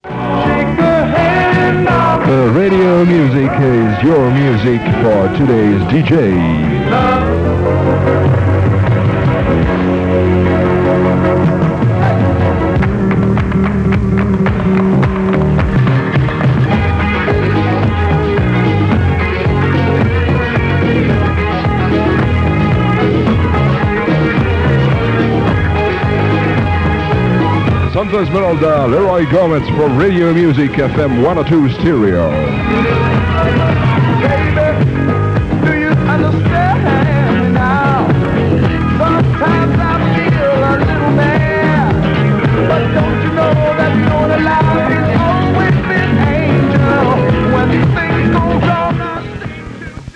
9f727864d83bbf7a4ae9893cb91b6a8e5e3272d1.mp3 Títol Radio Music Emissora Radio Music Titularitat Tercer sector Tercer sector Musical Musical Descripció Programació musical amb identificació.